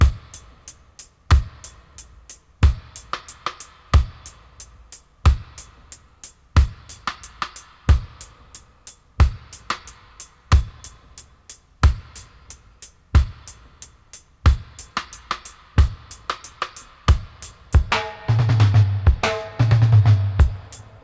90-FX-04.wav